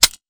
weapon_foley_drop_14.wav